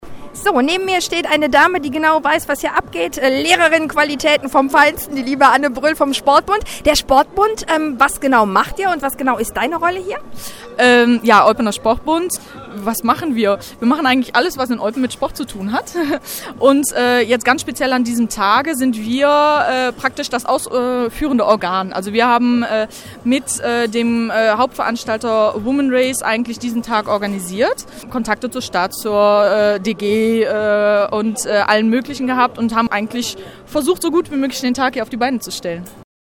Unter dem Motto BeActive ging gestern die Europäische Woche des Sports an den Start. Auftaktveranstaltung war der Ladies Run in Eupens Innenstatdt.